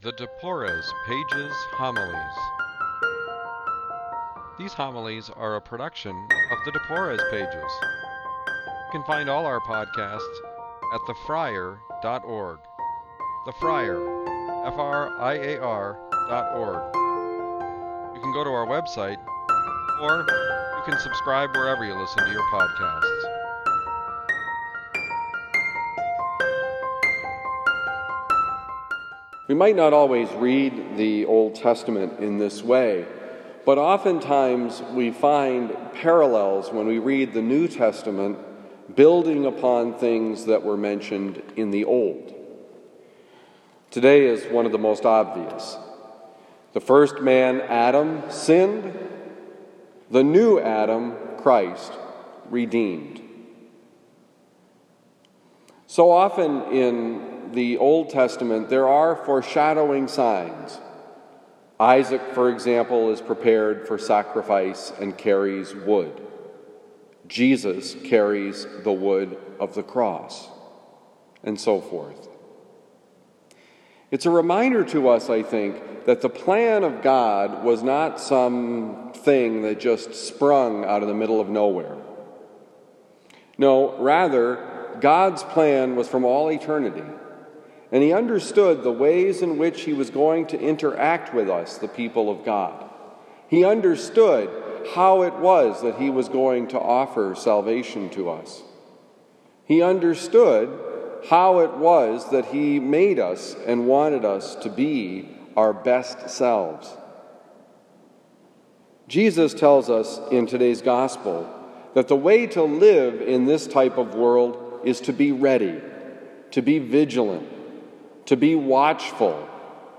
Homily for the 29th Tuesday of Ordinary Time, given at Christian Brothers College High School, October 22, 2019.